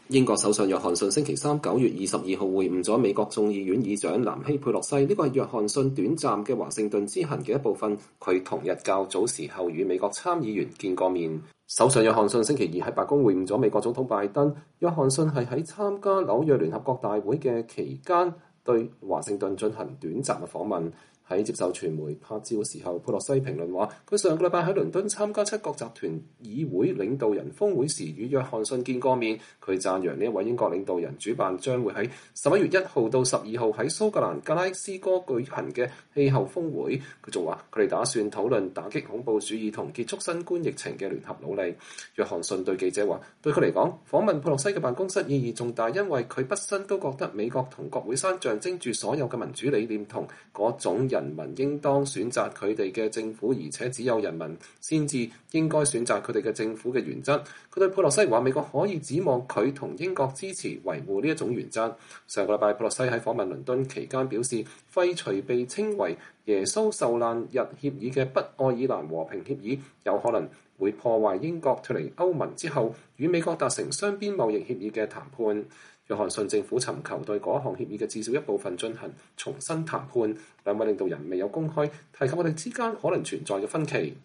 在接受媒體拍照時，佩洛西評論說，她上星期在倫敦參加七國集團議會領導人峰會時與約翰遜見了面。